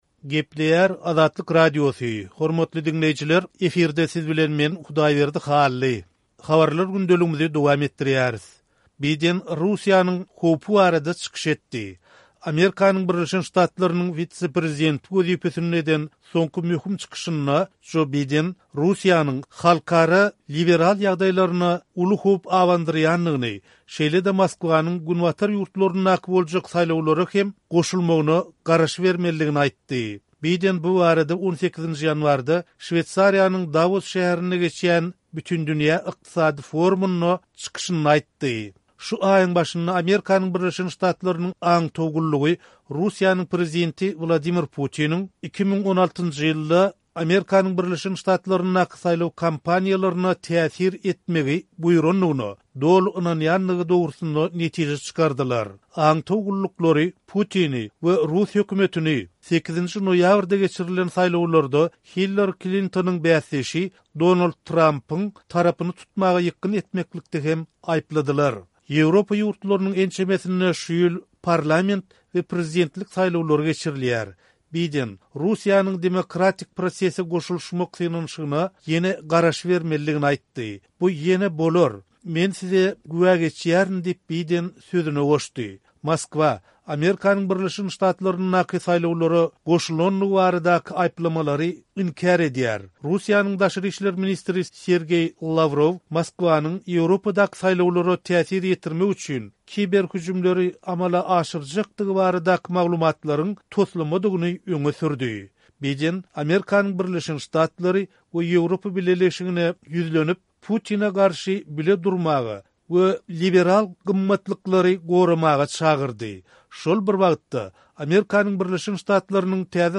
Biden Russiýanyň howpy barada çykyş etdi